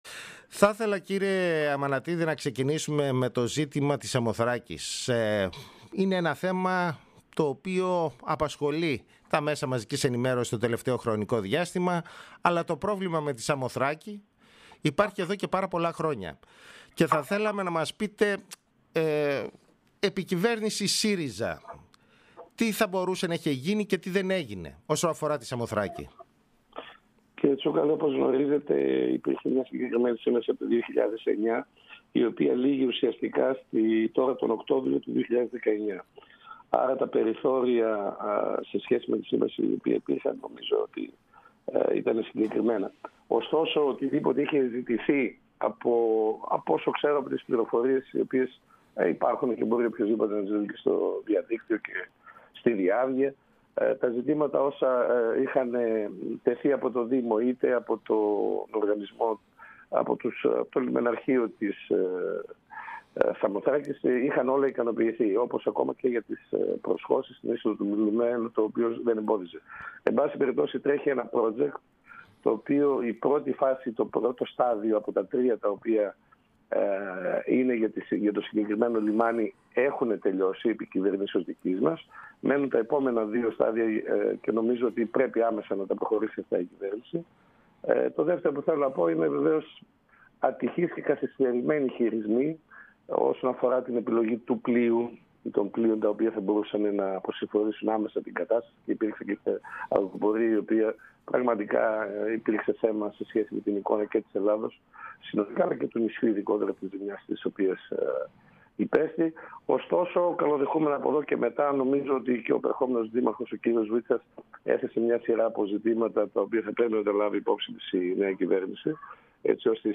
Ο Γιάννης Αμανατίδης στο Πρώτο Πρόγραμμα της ΕΡΑ (ηχητικό)